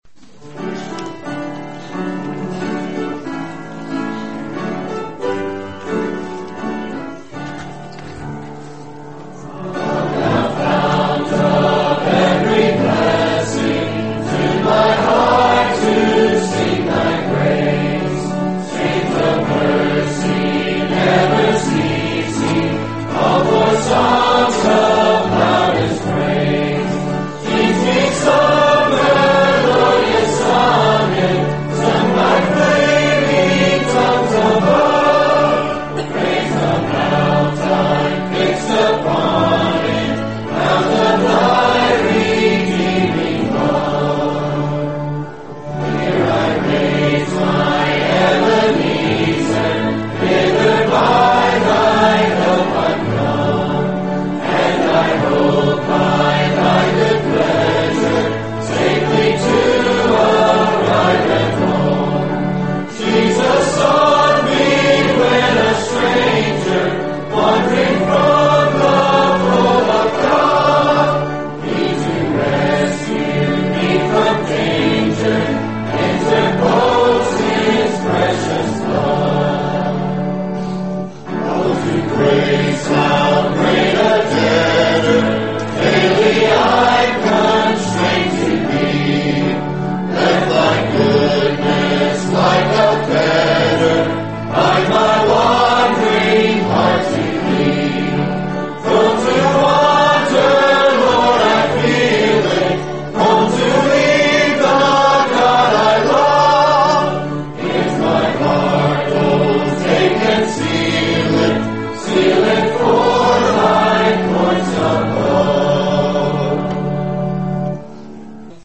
Come thou Fount- Grace camp at Bentley La